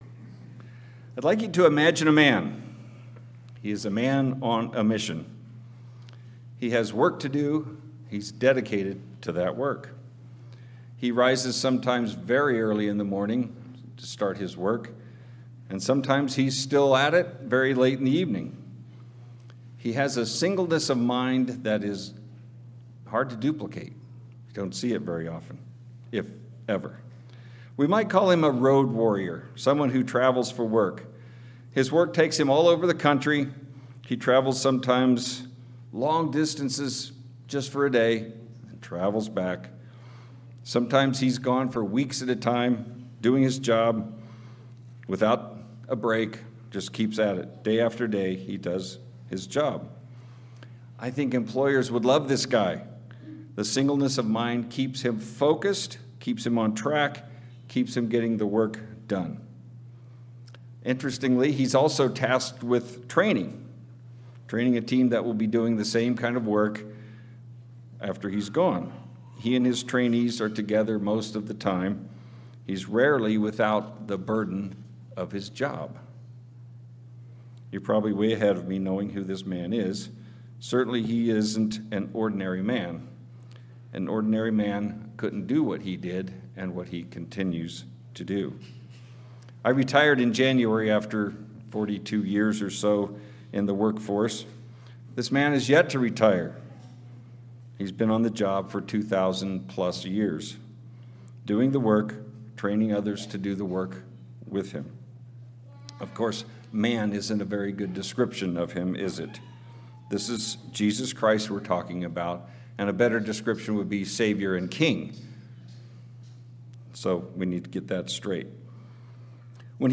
This sermon explores just one day in the life of Jesus Christ, showing it was full of purpose, compassion and patience.
Given in Kennewick, WA Chewelah, WA Spokane, WA